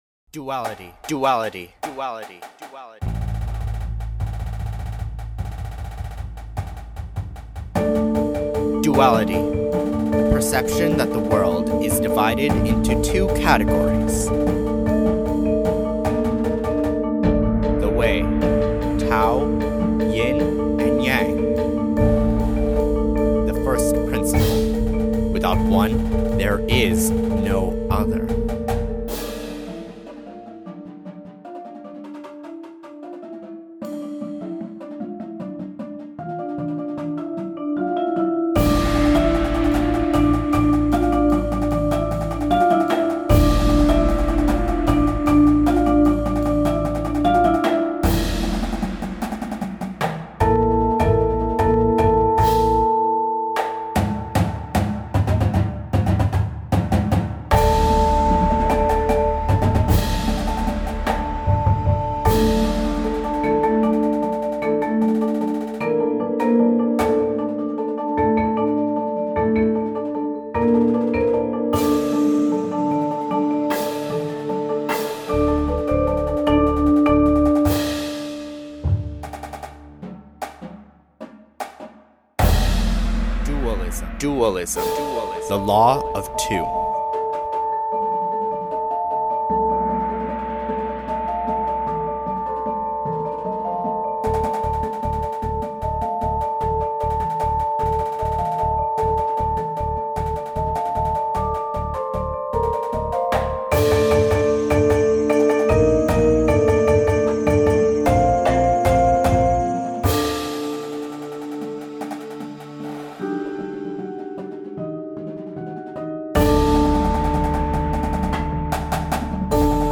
Grade Level: Jr. High - (For The Beginning Drum Line)
• snare
• tenors (4 or 5)
• bass drum (3 to 5)
• cymbals
• aux percussion (2)
• 4 marimba
• 2 synth